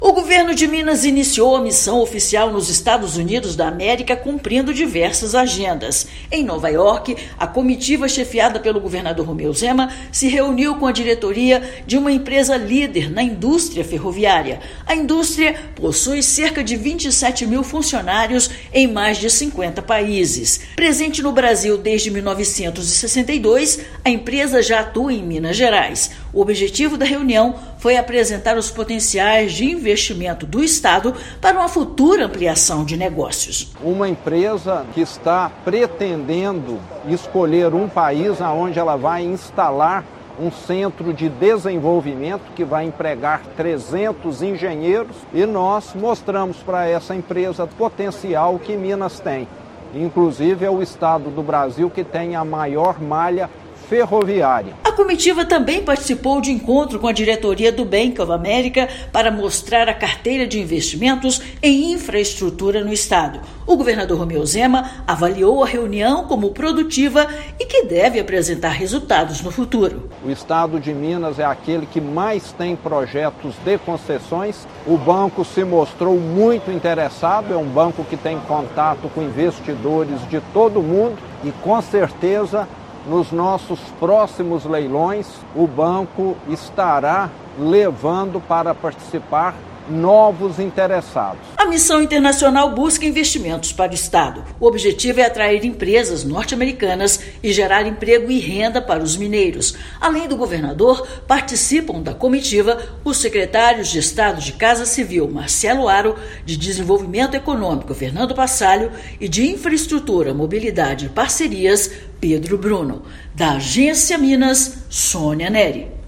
Comitiva apresentou os potenciais de MG, especialmente na área de infraestrutura, como a concessão de rodovias. Ouça matéria de rádio.